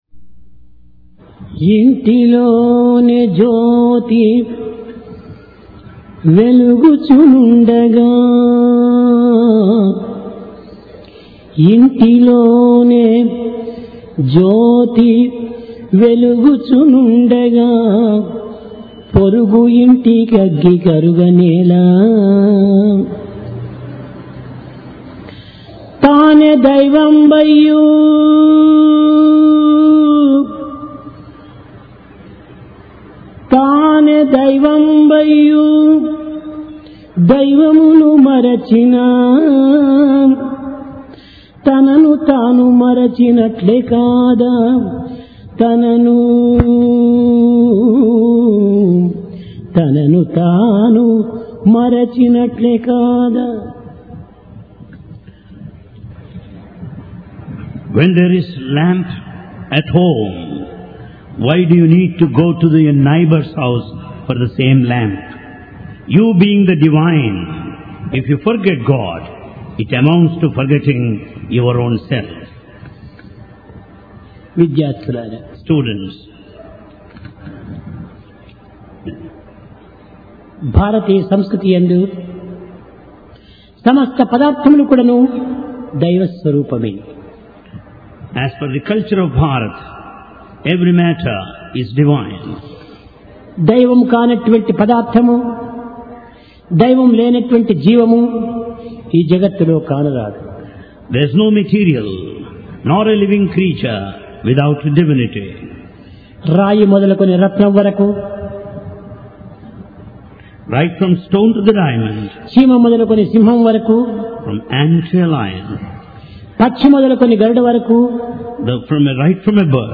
Sai Darshan Home Date: 14 Jul 1996 Occasion: Divine Discourse Place: Prashanti Nilayam Love And Faith - Qualities Of A True Devotee When there is lamp, why go to neighbour's house.